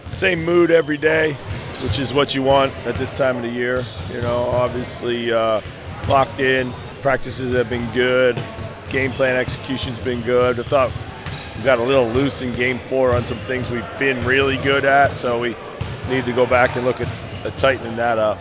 The Minnesota Timberwolves look to grab a spot in the Western Conference semifinals when they visit the Los Angeles Lakers in Game Five of their opening round N-B-A playoff series tonight in L-A. The Wolves lead the best-of-seven series three-games-to-one after a 116-113 home win in Game Four. Minnesota head coach Chris Finch says his team has been good in this series and they need more of it.